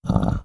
Monster_Hit4.wav